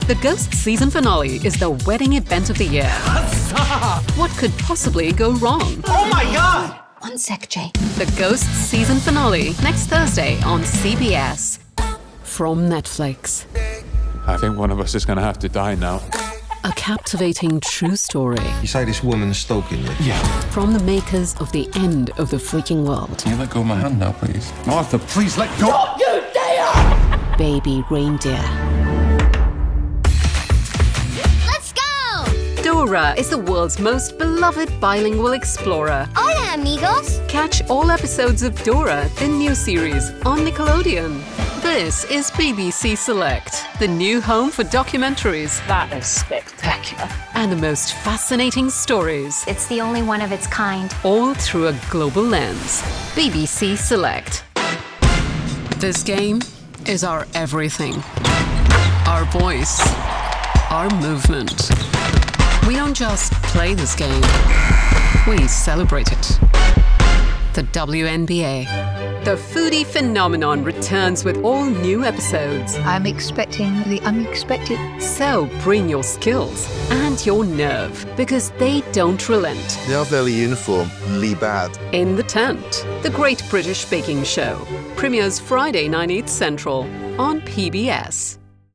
• 3. Promos